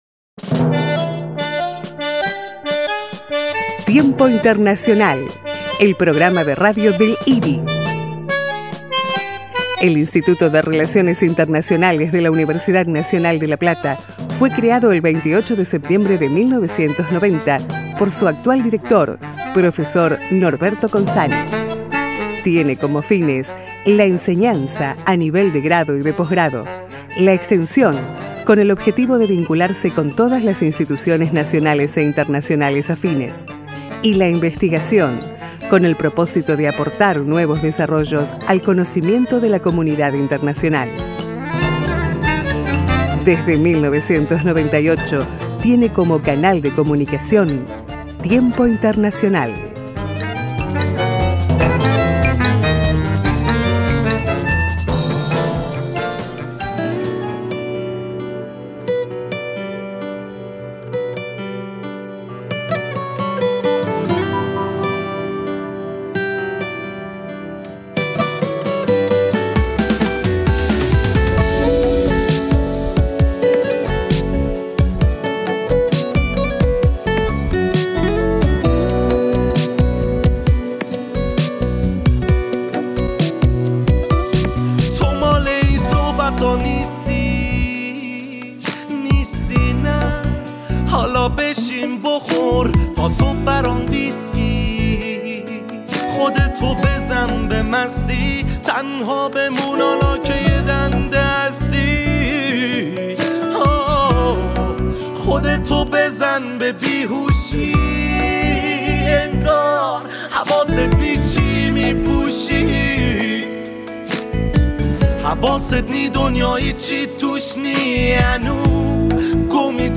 Track 1: Entrevistas a: Embajador de Irán en la República Argentina Ahmad Reza Kheirmand.